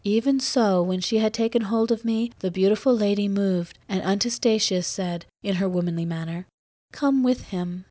We take 100 test samples from the dev-clean subset of LibriTTS for testing.